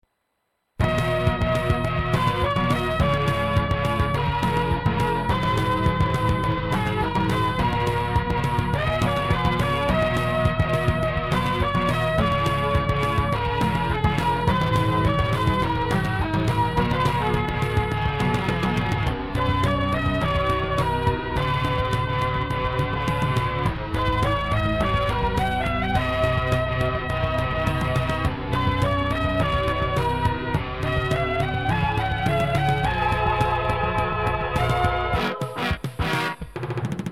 シューティングゲーム風BGMその1
制作環境：Ｘ−６８０３０ ＋ ＳＣ-８８ＶＬ
ということで、私もシューティングゲーム風BGMに挑戦してみました。
・・・ところで、この曲ですが、どうも縦シューよりは横シューのイメージではないかという気がします。